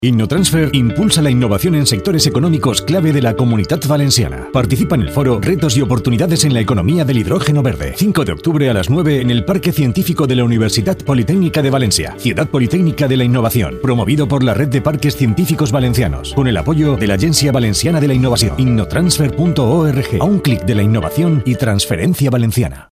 Campaña Innotransfer 2022 en Radio SER